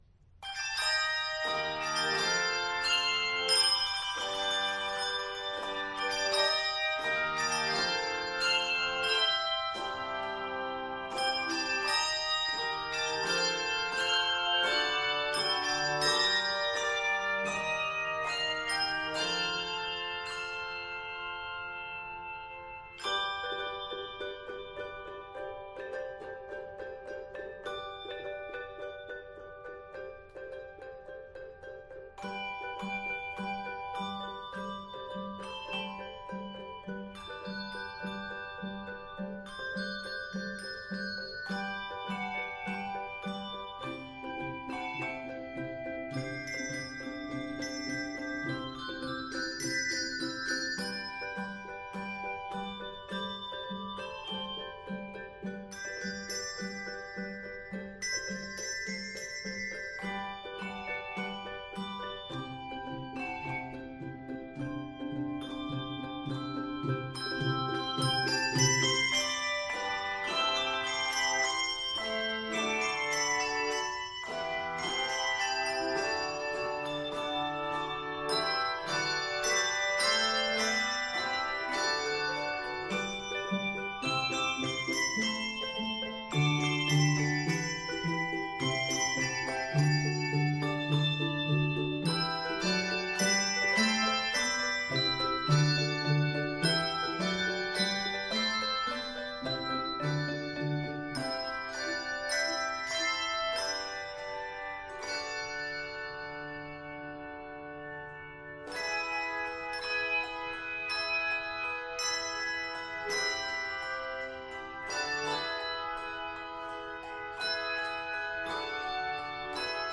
handbells
Keys of G Major and E Major.